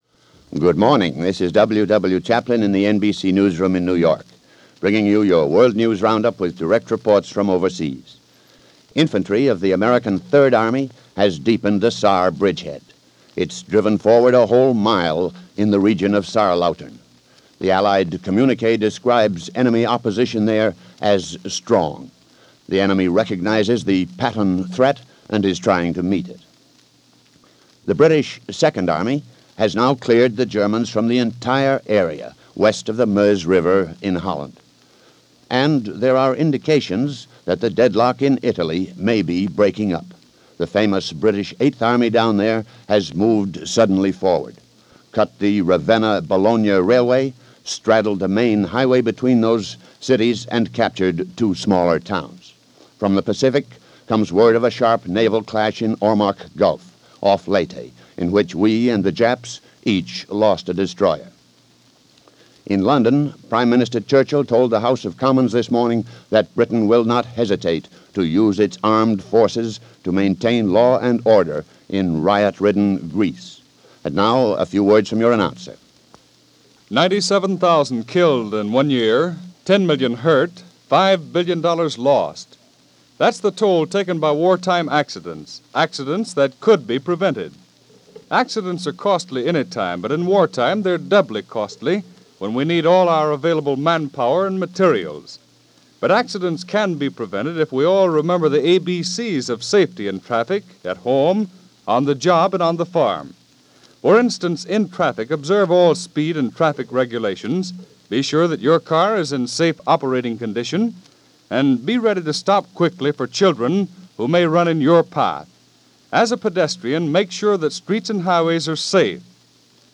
The Trouble With Greece - December 5, 1944 - news for this day from NBC's News Of The World - rioting in Greece.